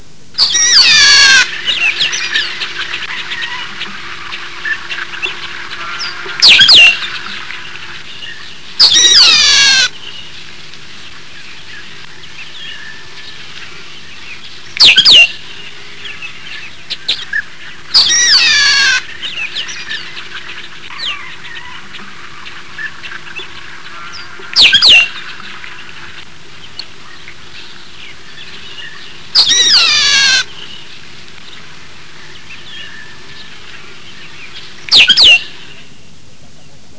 Una de ellas parece ser el Trile (Agelaius thilius)(canto:
trile.wav